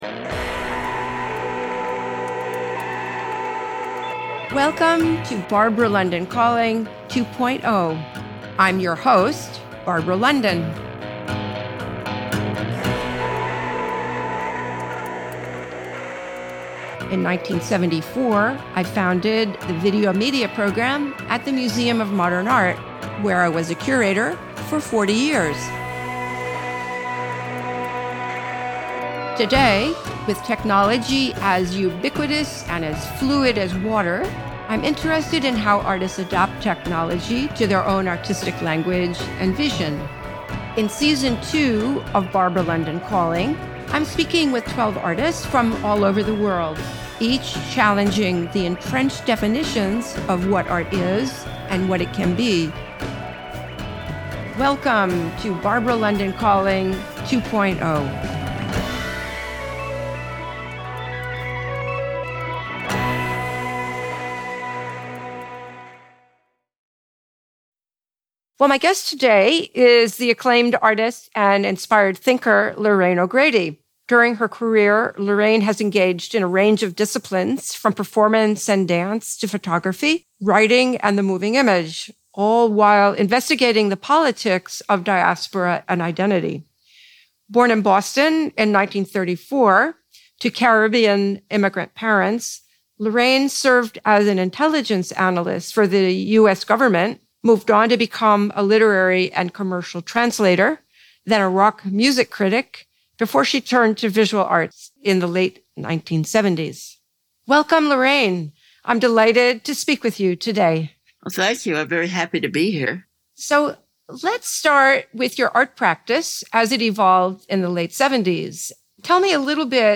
2.03 | Lorraine O’Grady — Barbara London interviews Lorraine O’Grady on her art practice and career, with a full transcript available here.